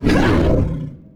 attack1.wav